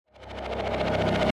Download Transition Beats sound effect for free.
Transition Beats